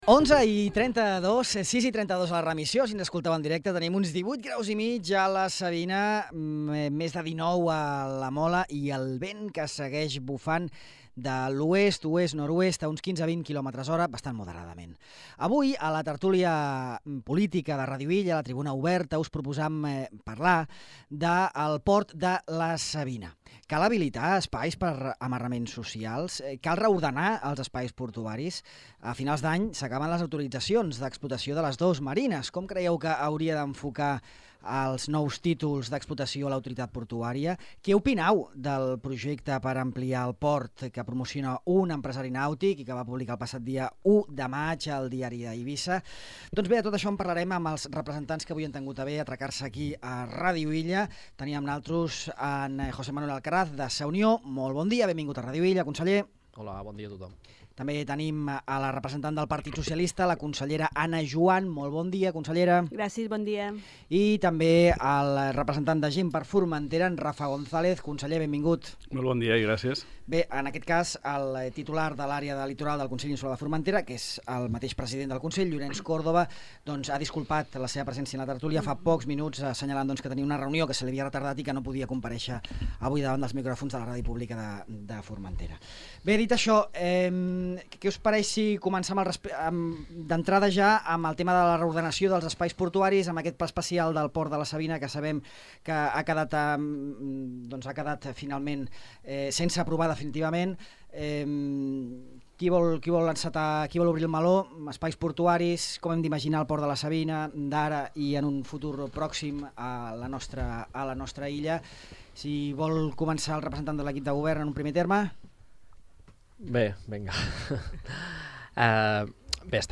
Tertúlia política: Sa Unió, GxF i PSOE rebutgen la proposta d'un empresari per ampliar el port de la Savina
El conseller de Sa Unió, José Manuel Alcaraz; el de Gent per Formentera, Rafa González; i la del PSOE, Ana Juan, han participat en la Tribuna Oberta, el debat polític de Ràdio Illa, que en aquesta nova edició ha girat al voltant del port de la Savina.